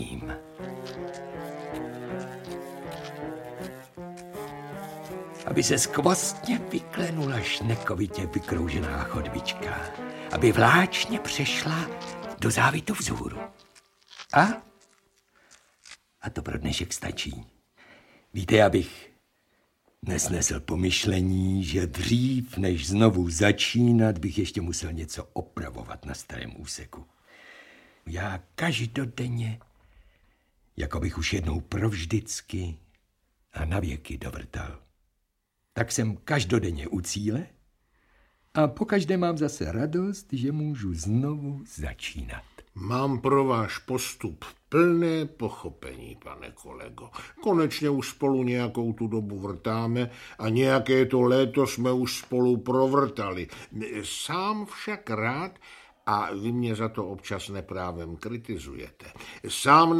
Audiobook
Read: Ivan Řezáč